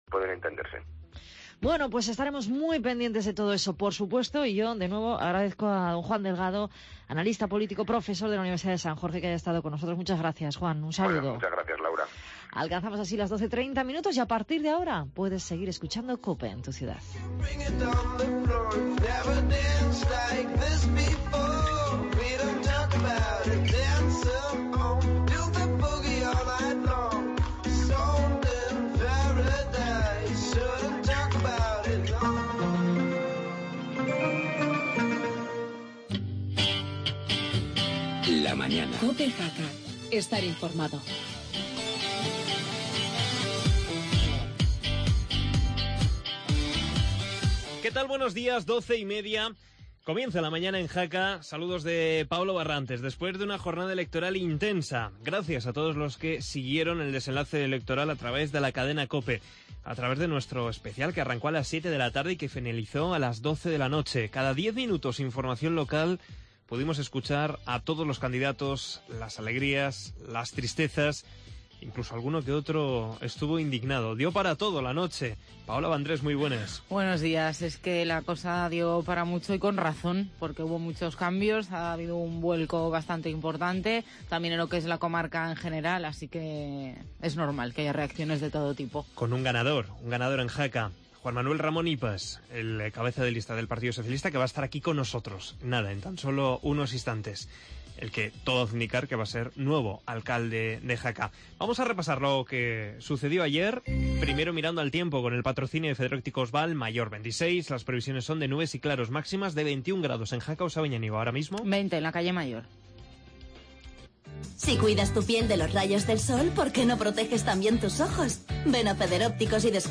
Actualidad, entrevista a Juan Manuel Ramón Ipas